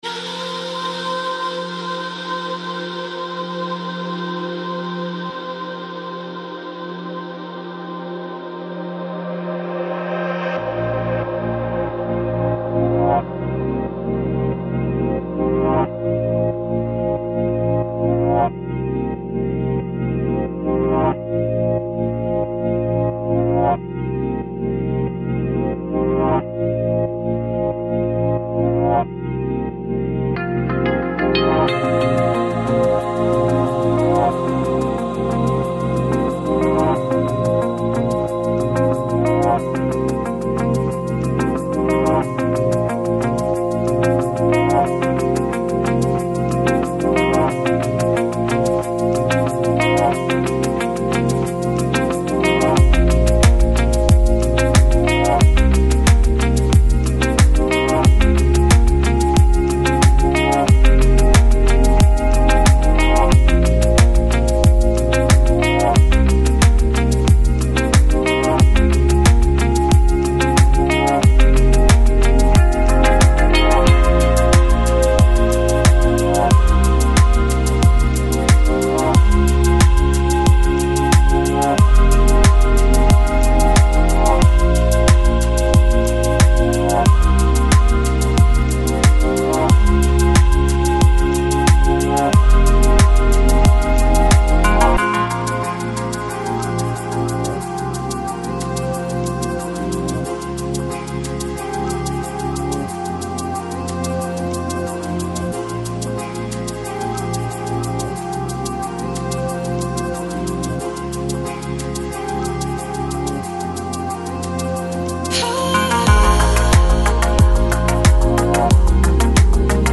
Chillout, Chillhouse, Downbeat, Lounge